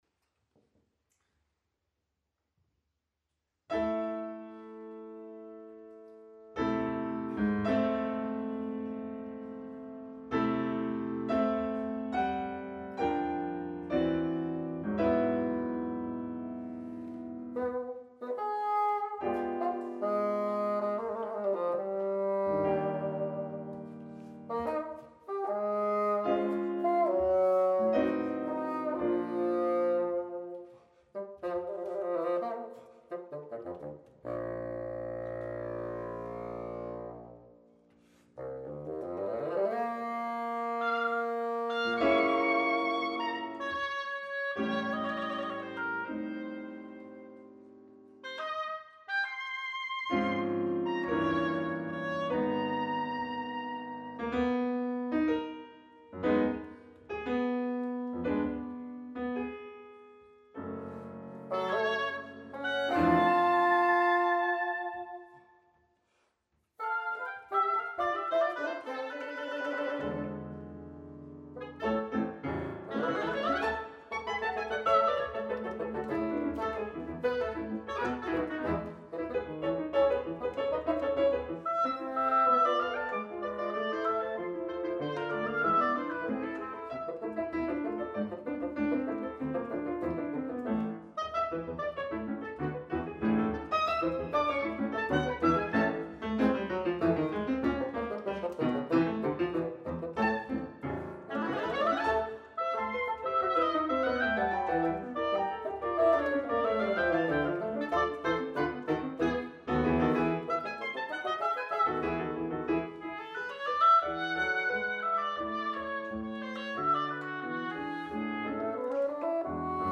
Trio for oboe, bassoon and piano Op.43 - West Cork Music
Venue: Bantry Library
Instrumentation Category:Trio Instrumentation Other: ob, bn, pf